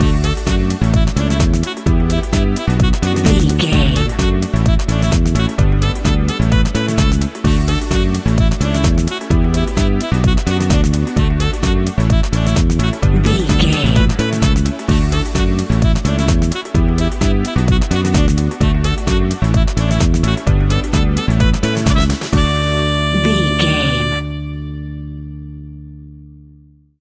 Aeolian/Minor
groovy
uplifting
driving
energetic
bass guitar
drums
strings
saxophone
piano
electric piano
electro
deep house
nu disco
synth
upbeat
instrumentals
funky guitar
wah clavinet
synth bass